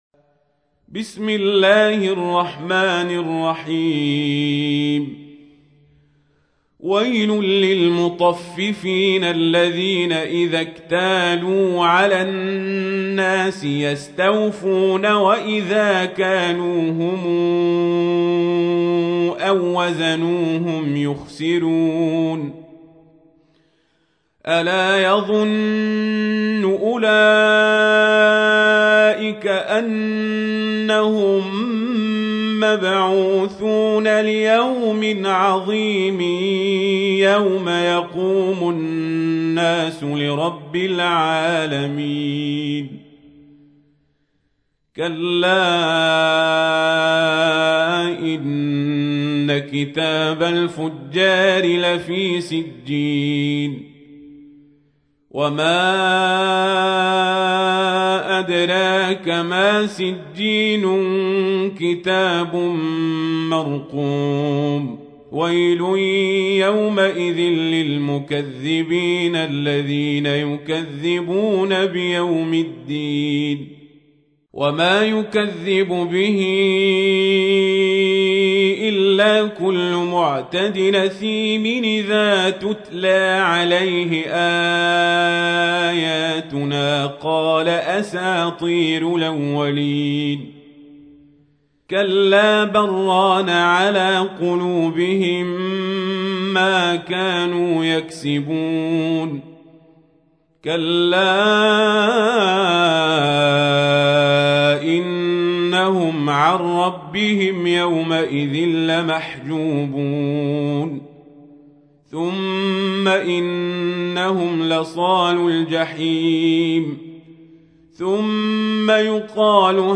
تحميل : 83. سورة المطففين / القارئ القزابري / القرآن الكريم / موقع يا حسين